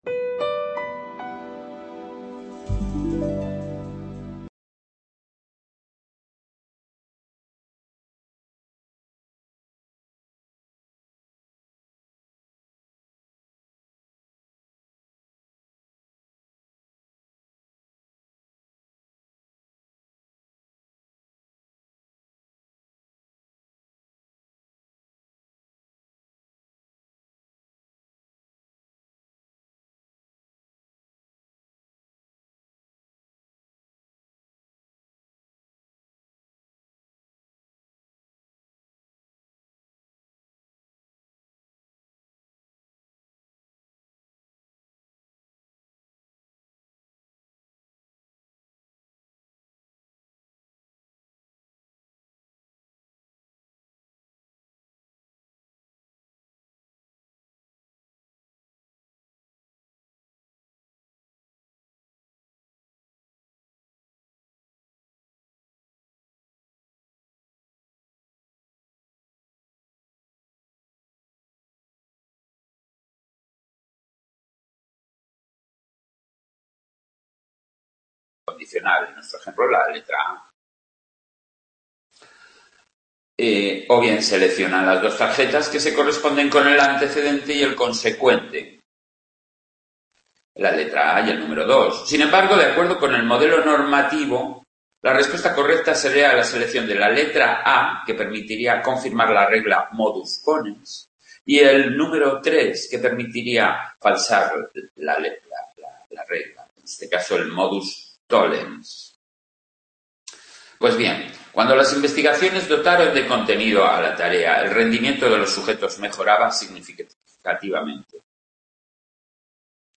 Grabación del final del Tema 5 de Psicología del Pensamiento